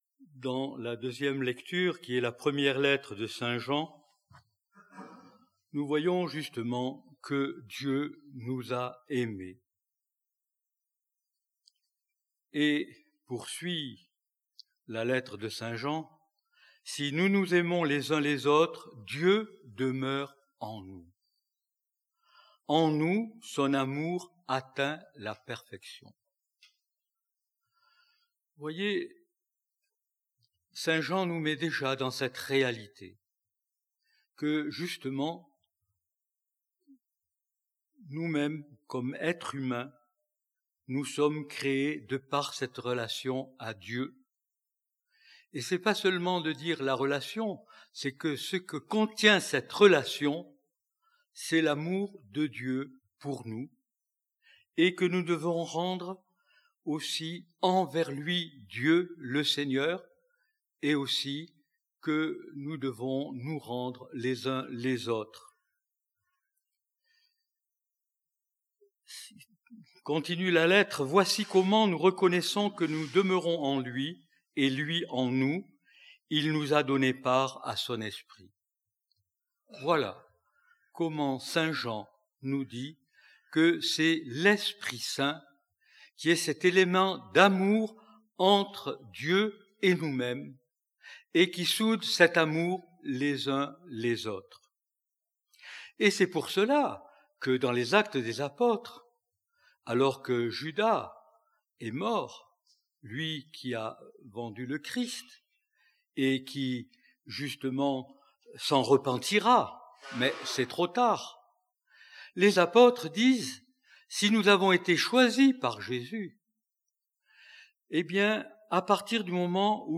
Pour ce septième dimanche de Pâques
Grâce à un enregistrement en direct